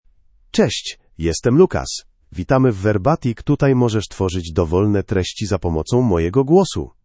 Lucas — Male Polish (Poland) AI Voice | TTS, Voice Cloning & Video | Verbatik AI
Lucas is a male AI voice for Polish (Poland).
Voice sample
Listen to Lucas's male Polish voice.
Lucas delivers clear pronunciation with authentic Poland Polish intonation, making your content sound professionally produced.